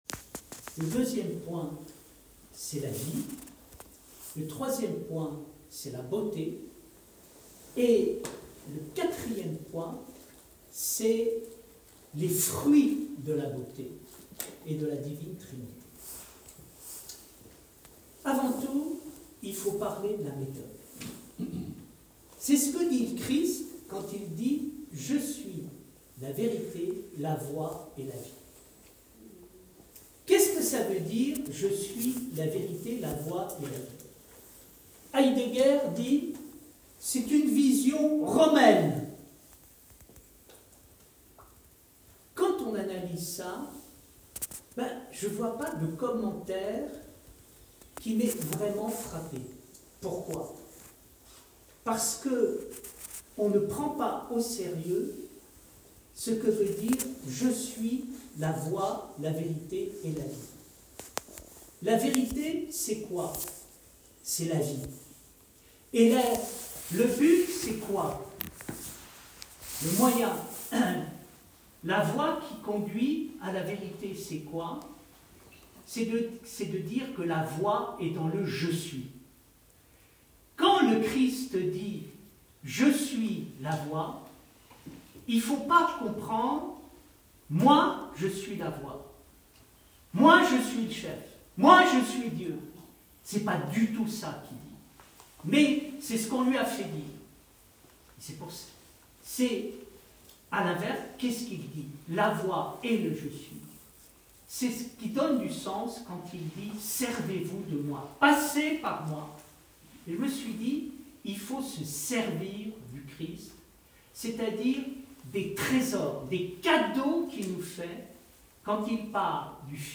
la-trinité-conférence-bv.m4a